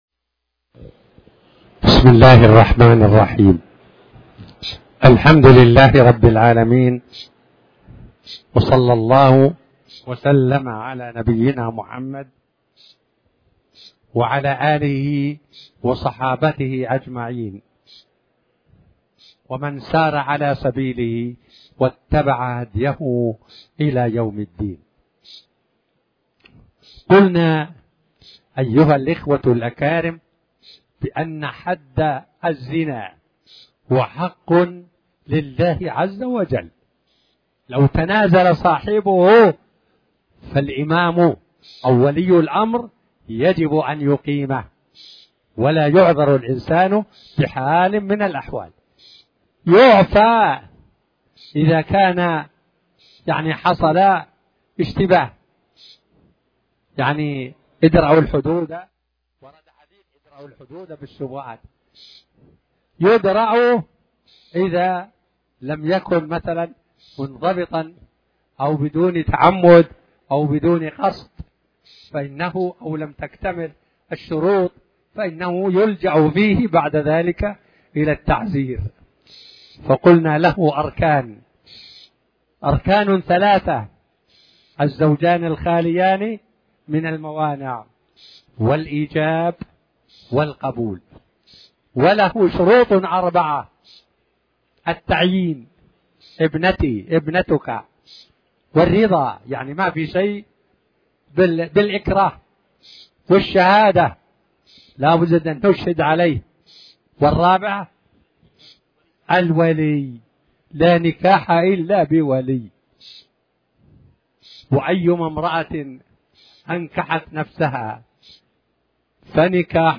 تاريخ النشر ٥ ربيع الثاني ١٤٤٠ هـ المكان: المسجد الحرام الشيخ